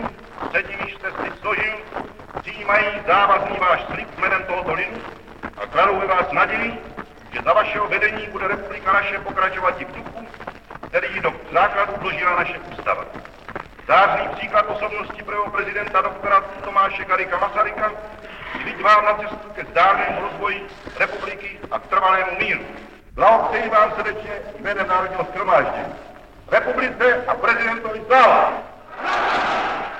Speeches by Presidents: Edvard Beneš
Audiobook